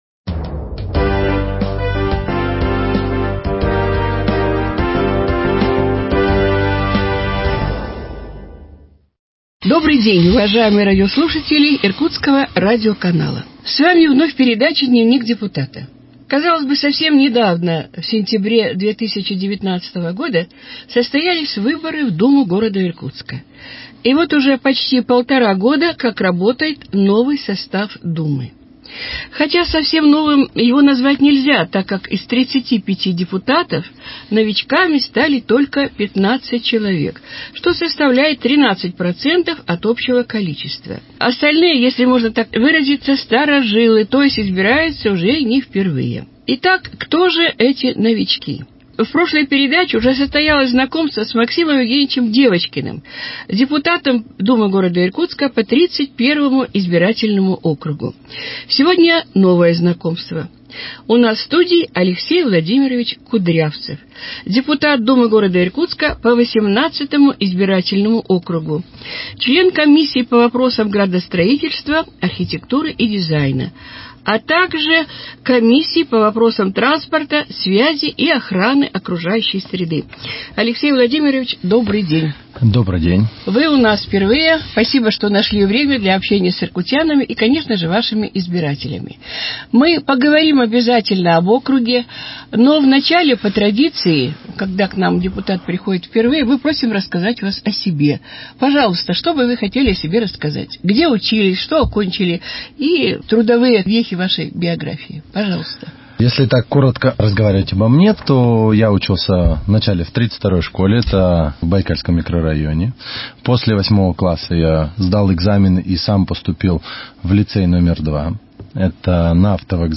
С депутатом Думы Иркутска по 18 избирательному округу Алексеем Кудрявцевым беседует